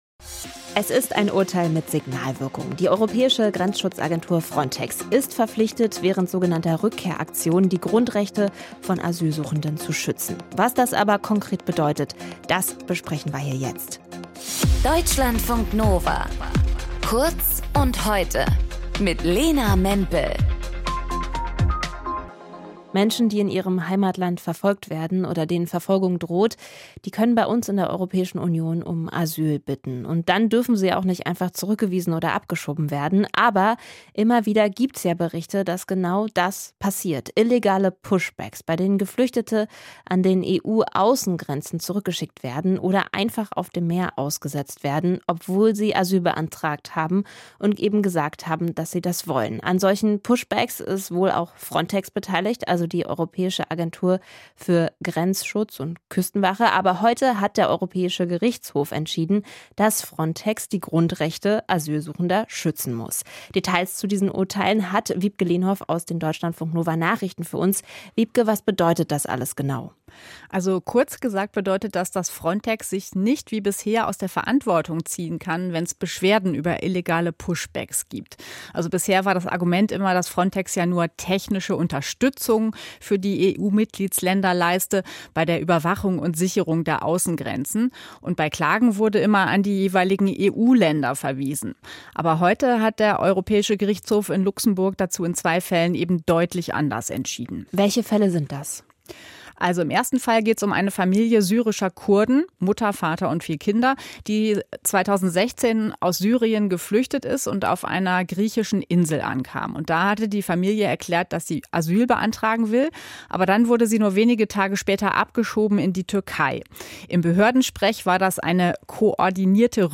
In dieser Folge mit:
Moderation:
Gesprächspartnerin: